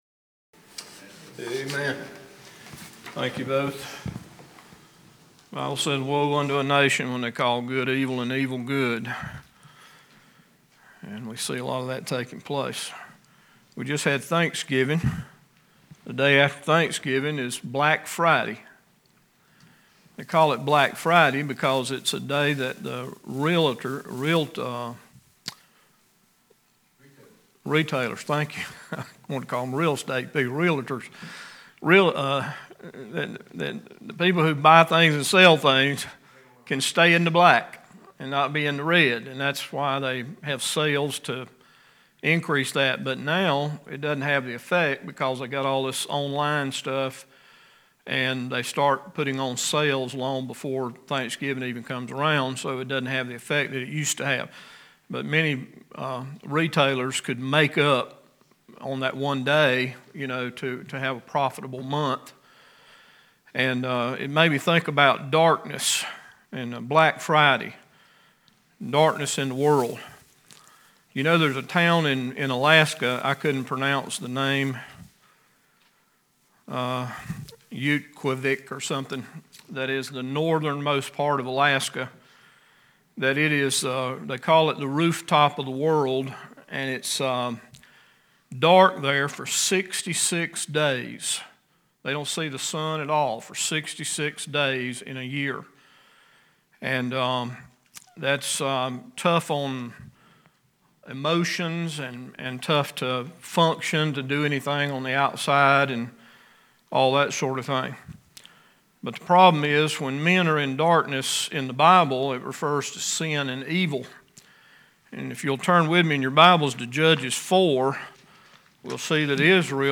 Judges Bible Study 3 – Bible Baptist Church